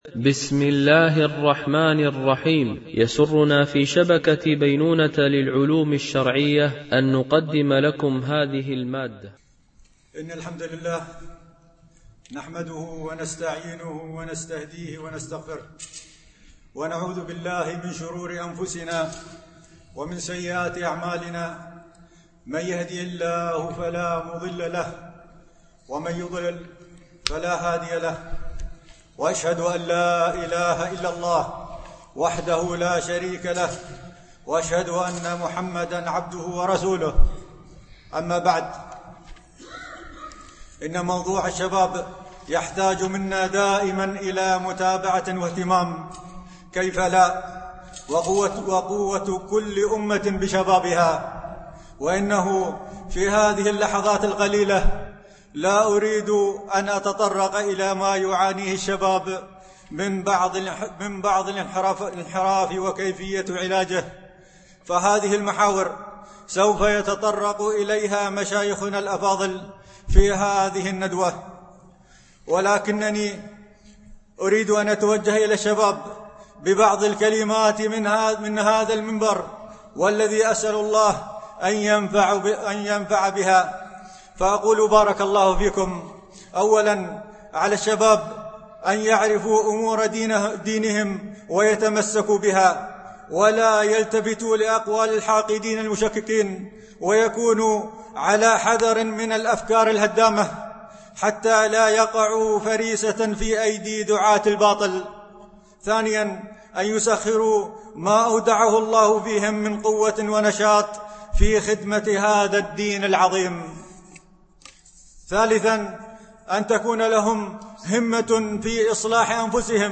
ندوة: الانحراف الأخلاقي عند الشباب، الأسباب... والعلاج
الشيخ: مجموعة من المشايخ